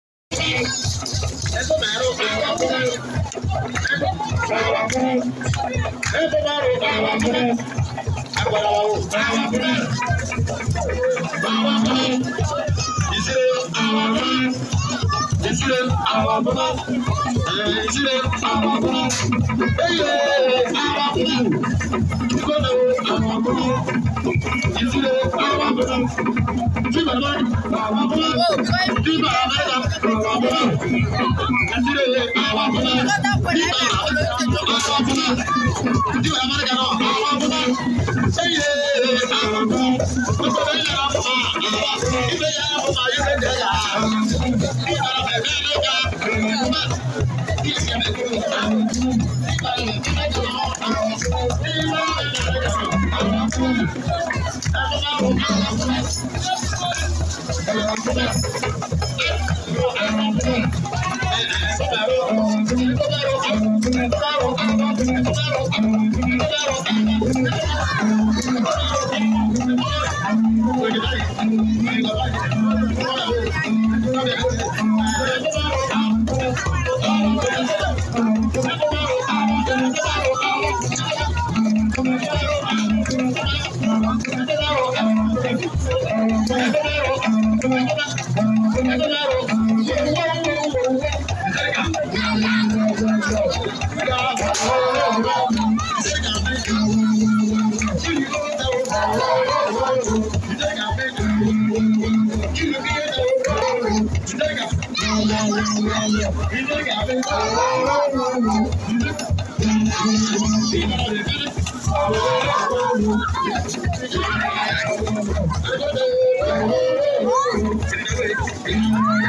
this play happened live at OGOLOMA KINGDOM MARKET SQUARE. This masquerade is called blacky-ekpo This Play Happened Live At Sound Effects Free Download.